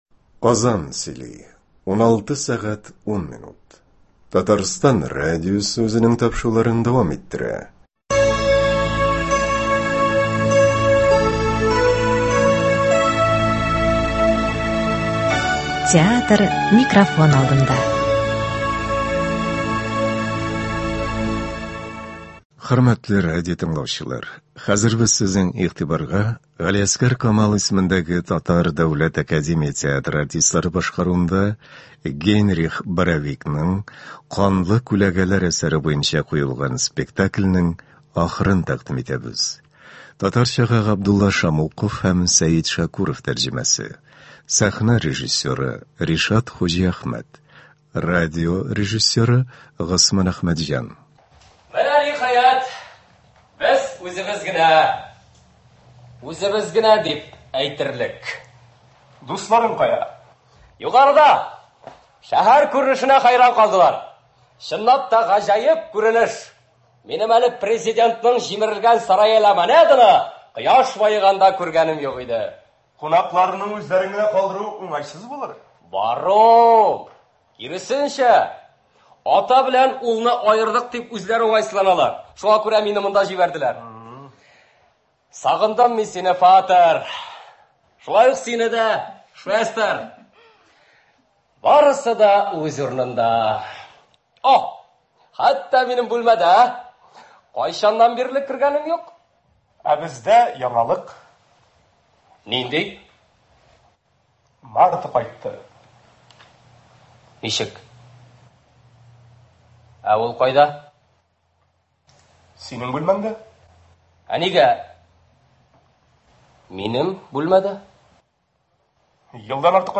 Г.Камал ис. ТДАТ спектакленең радиоварианты.